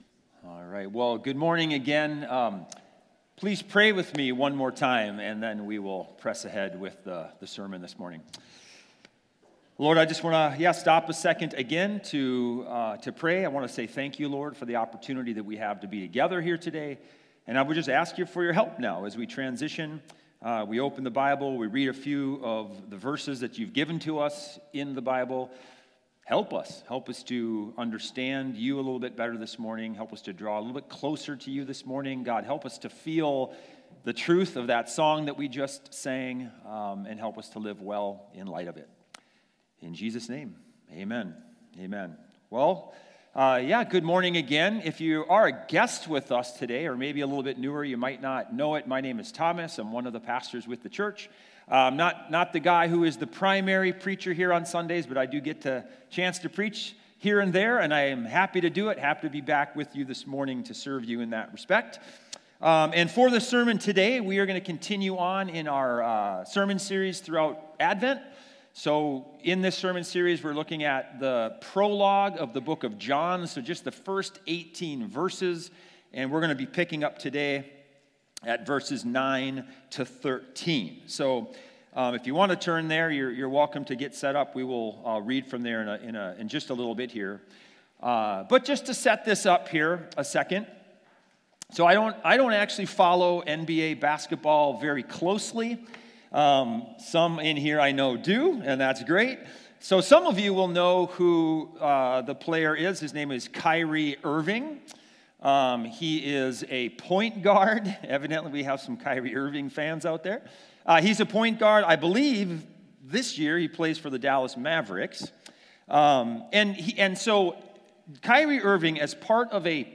Weekly Sunday Sermons from Christ Redeemer Church in Cottage Grove, MN